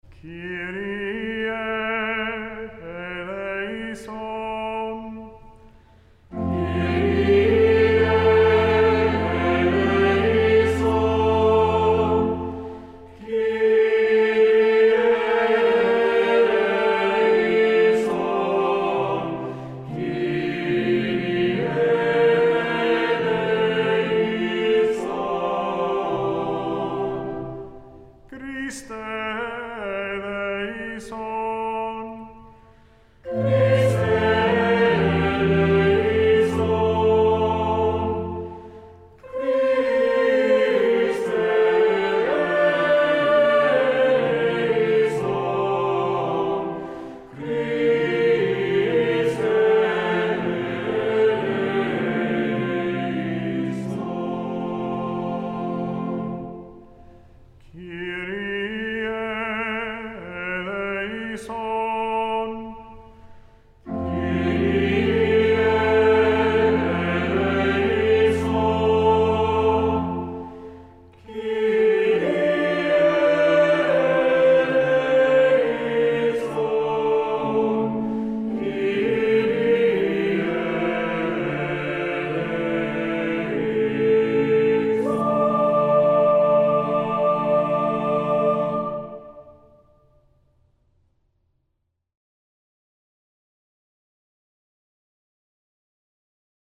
Voicing: Two-part equal; Cantor; Assembly